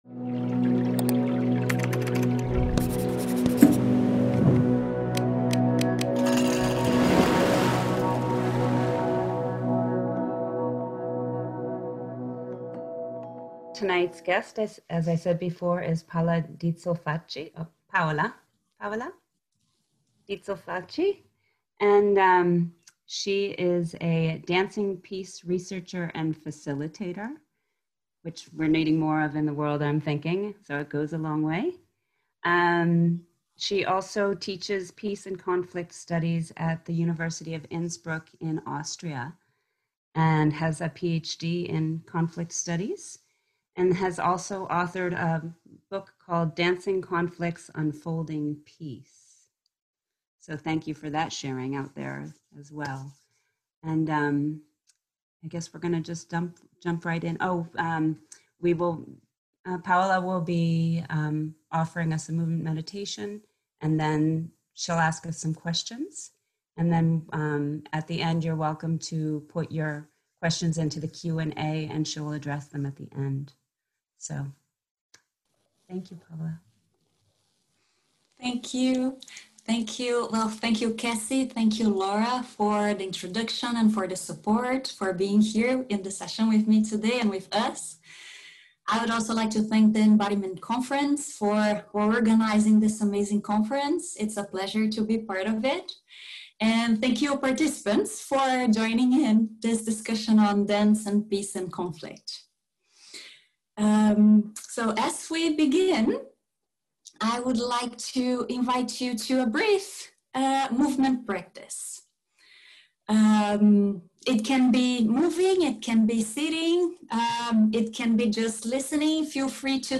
Likely soothing